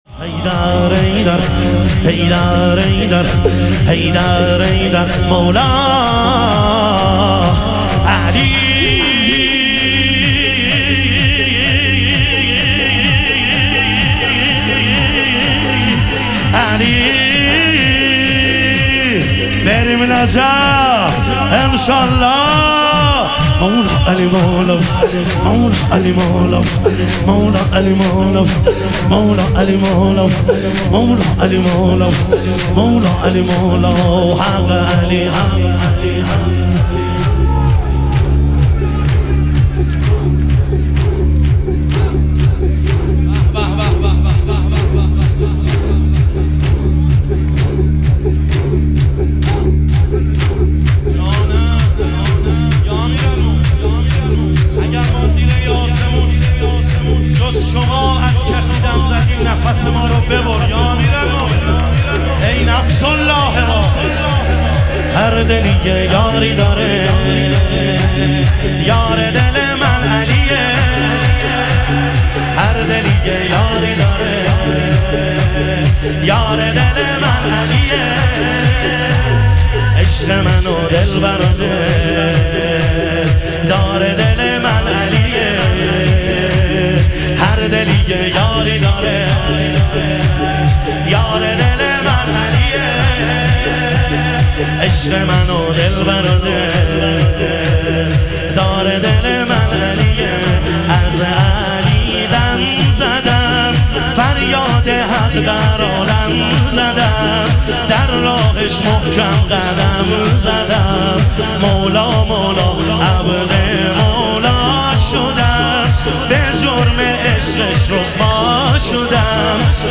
اربعین امیرالمومنین علیه السلام - شور